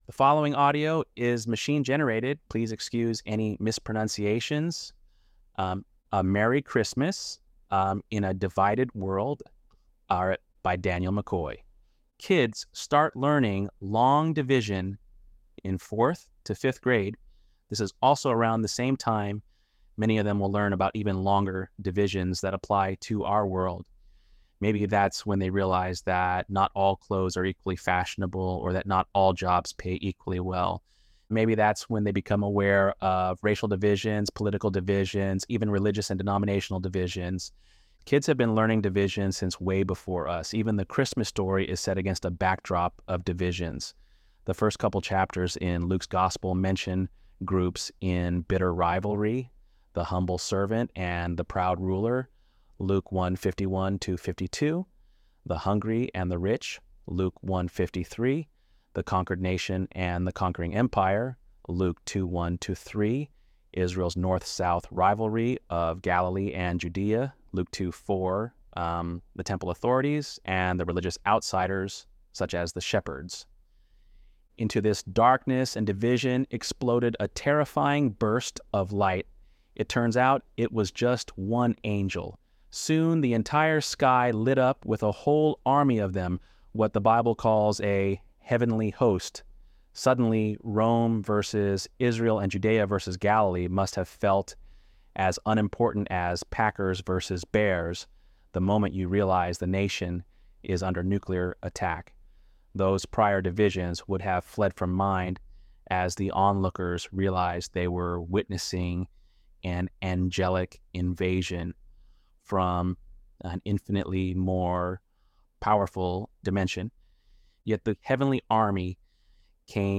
ElevenLabs_Untitled_project-35.mp3